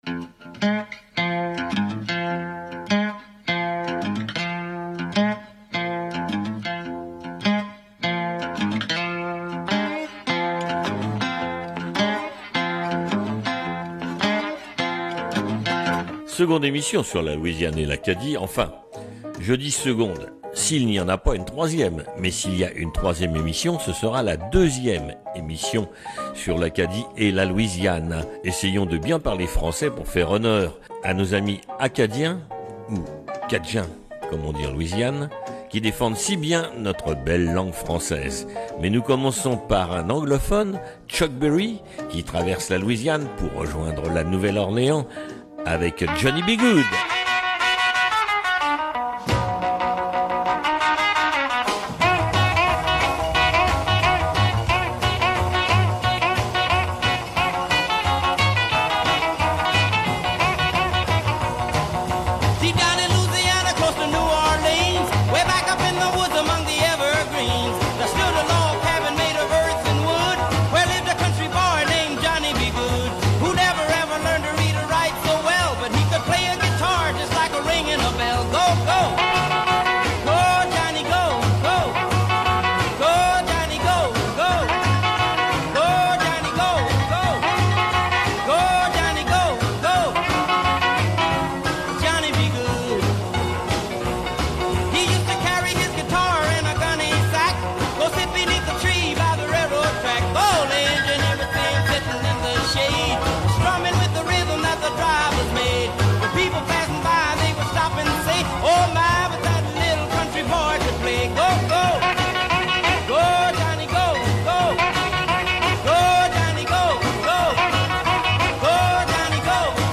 La route du blues est une voie imaginaire qui va de la Nouvelle Orléans à Détroit , deux villes fondées par les français , en longeant le Mississipi et qui après la Guerre de Sécession fut la grande voie de migration des afro-américains vers la Liberté .Ils ont arrosé cette route de leur musique . Aujourd’hui l’émission se penche sur les Bayous de Louisiane et le Zydéco musique noire chantée généralement phonétiquement en français , parfois en anglais .